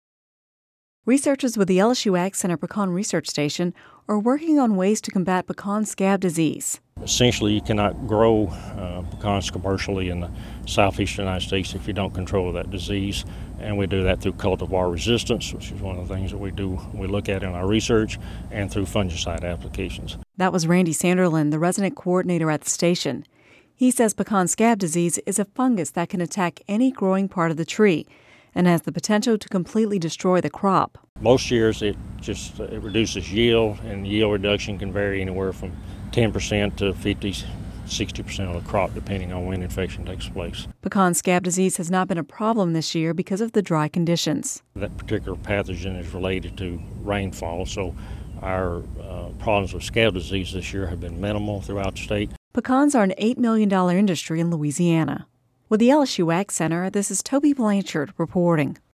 (Radio News 11/15/10) Researchers with the LSU AgCenter Pecan Research Station are working on new ways to combat pecan scab disease.